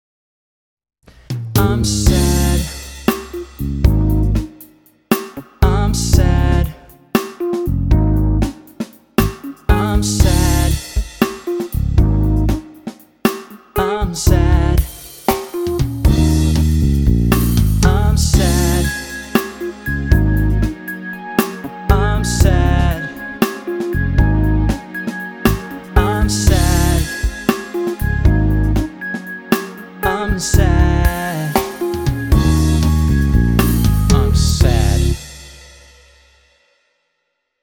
BPM59
Audio QualityPerfect (High Quality)
infamous for being amazingly slow.